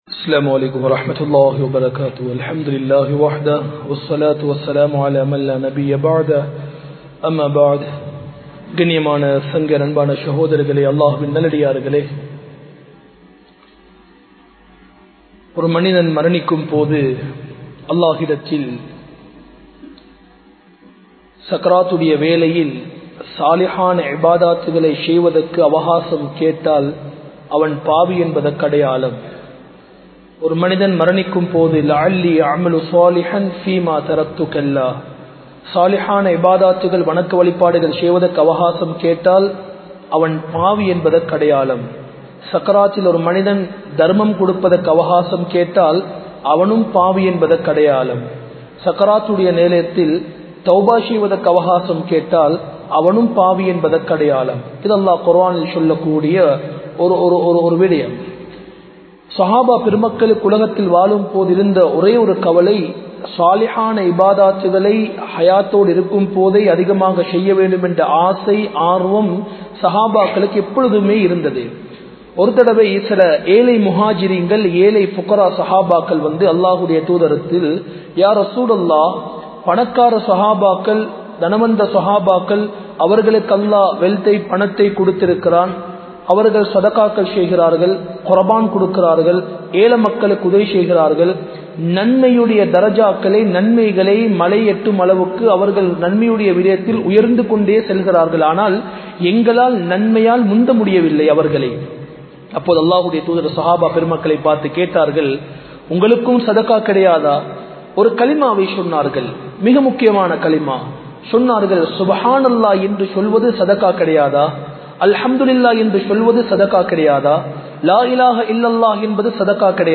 Bayans
Colombo 04, Majma Ul Khairah Jumua Masjith (Nimal Road)